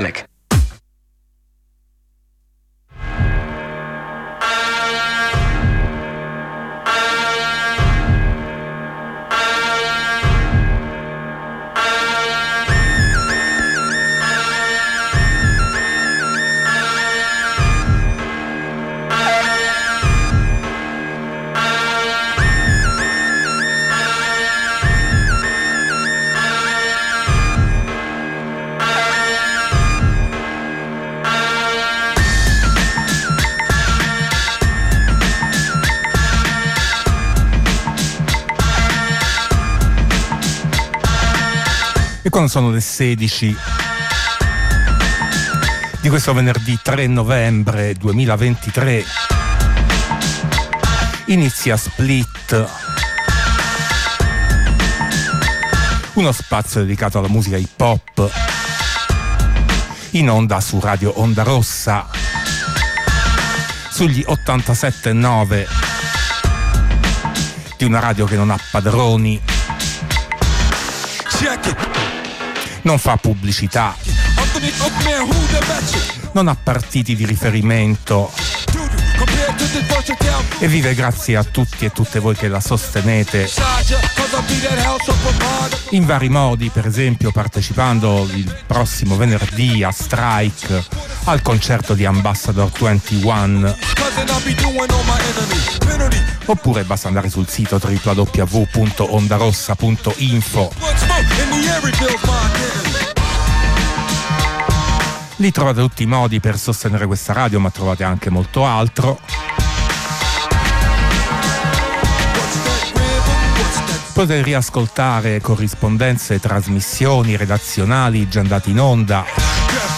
Split è uno spazio dedicato alla musica hip hop senza confini territoriali e temporali.